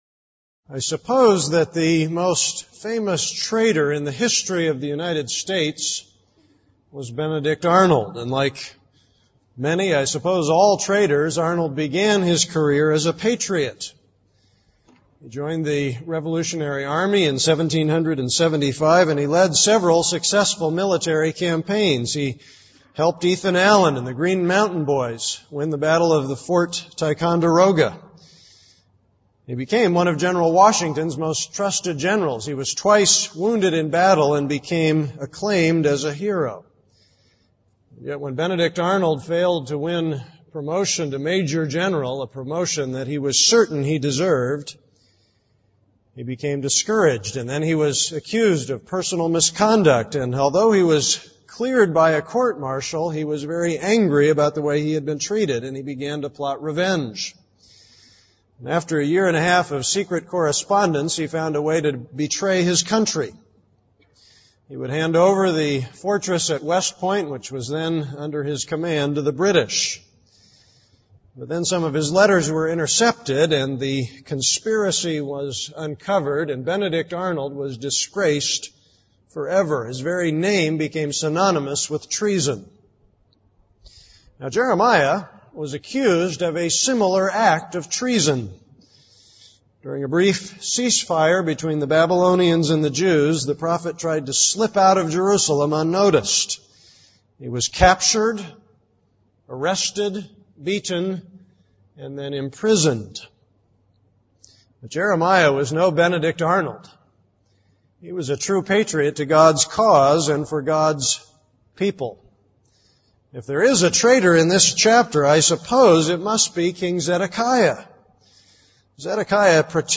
This is a sermon on Jeremiah 37:1-21.